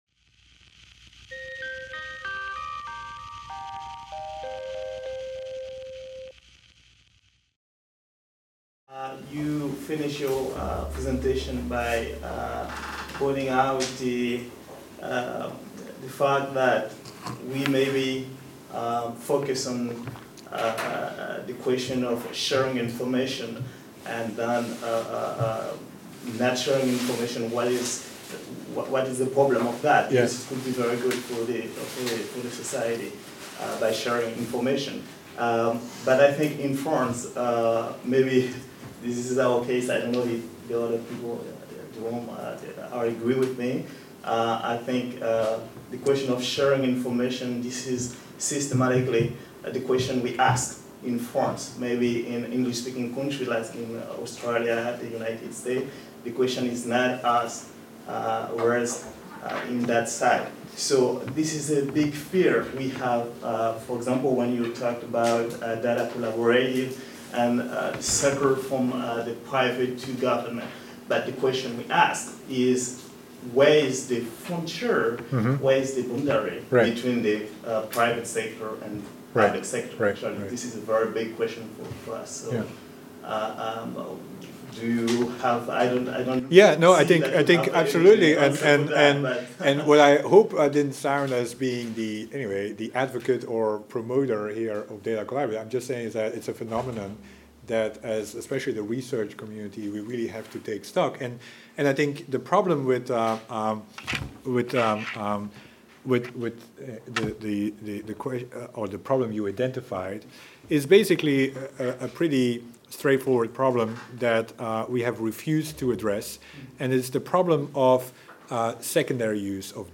Discussion - The Promise and Perils of Public-Private Partnerships around Data for Social Good | Canal U